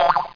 1 channel
drop.mp3